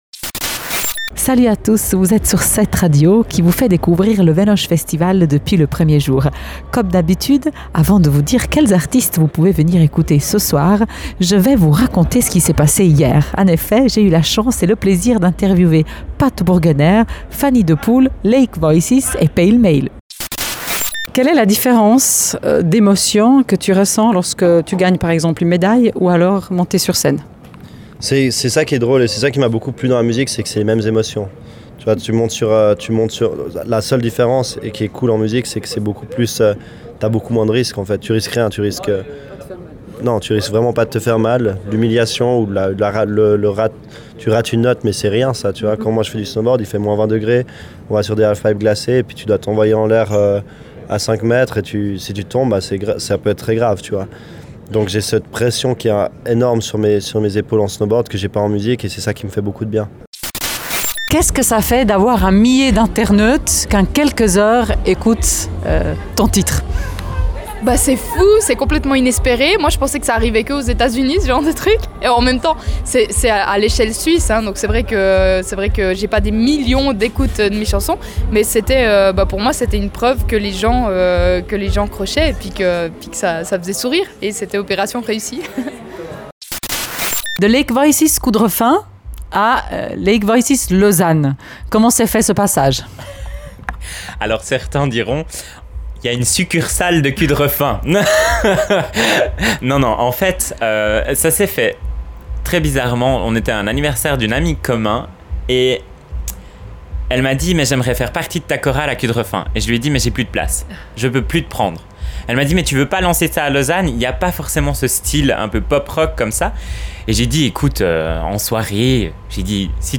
Les extraits des interviews à Pat Burgener